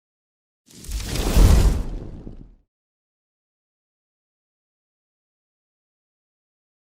Fire Whoosh Sound Effect.wav